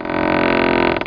1 channel
doorcrk2.mp3